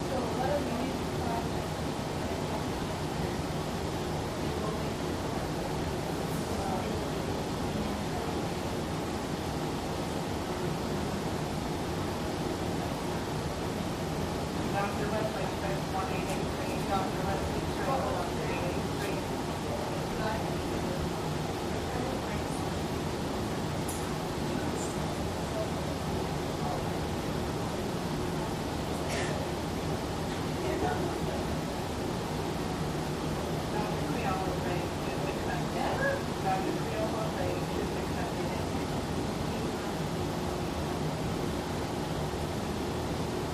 Hospital Hallway Loop With AC, Walla, Announcements